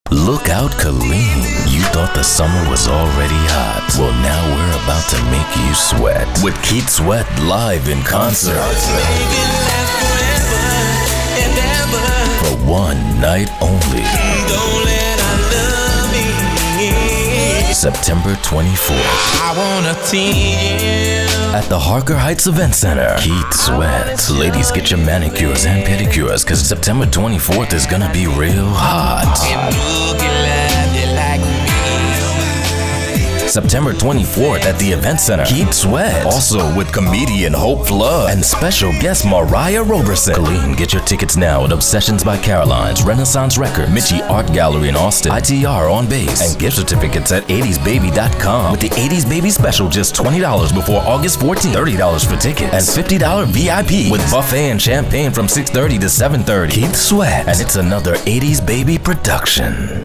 Strong, Versatile, African American, Urban, Caucasian, Caribbean, New York. Professional, Smooth.
mid-atlantic
Sprechprobe: Sonstiges (Muttersprache):